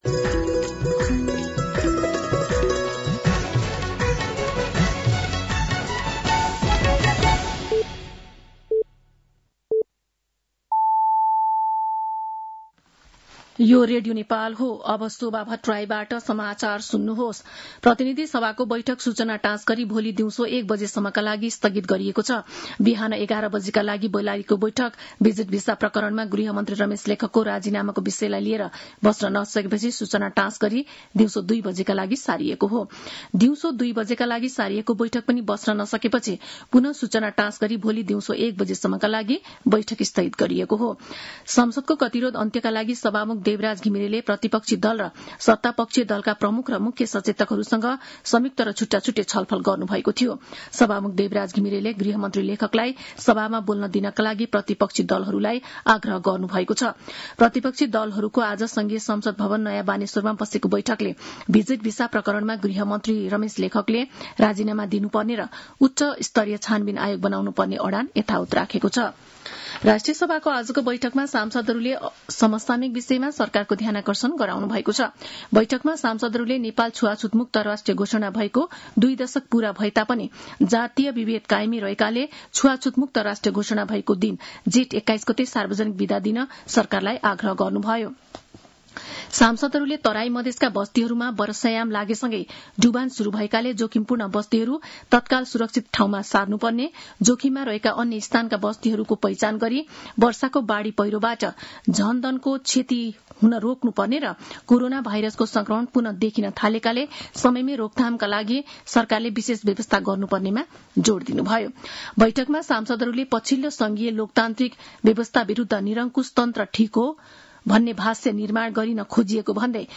साँझ ५ बजेको नेपाली समाचार : २१ जेठ , २०८२